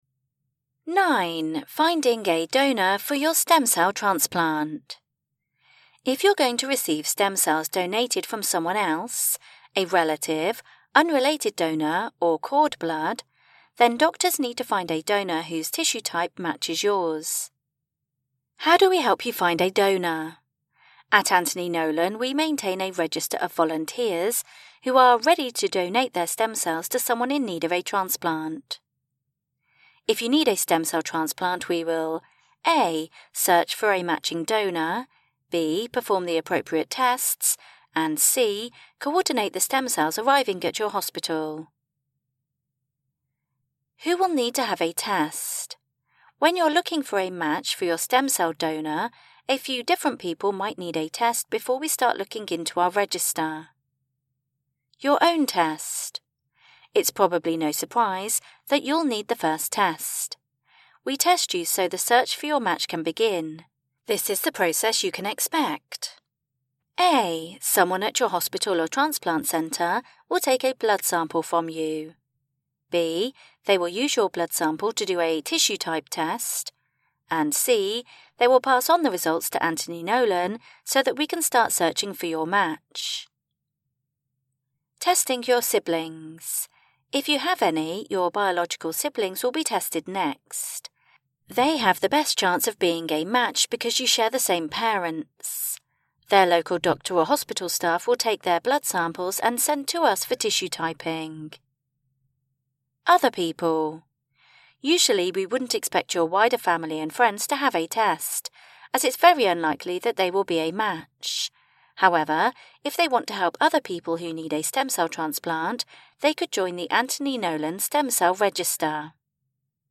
Audio version of Anthony Nolan's patient information: Finding a donor for your stem cell transplant